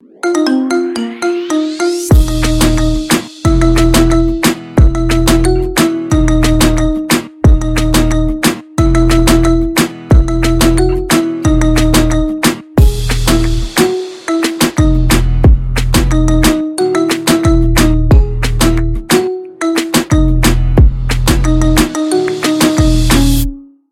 маримба , ремиксы